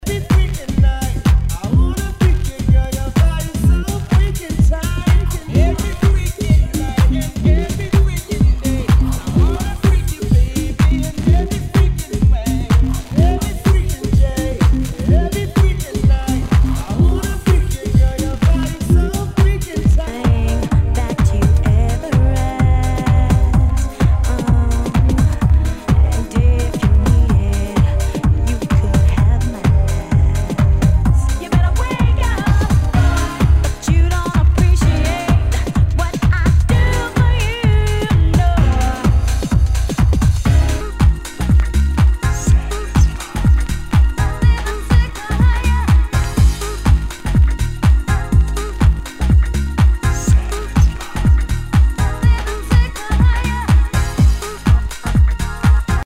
HOUSE/TECHNO/ELECTRO
ナイス！ヴォーカル・ハウス！
盤に傷あり全体に大きくチリノイズが入ります。